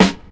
• 90s Hot Rap Steel Snare Drum Sound G Key 537.wav
Royality free acoustic snare sound tuned to the G note. Loudest frequency: 1263Hz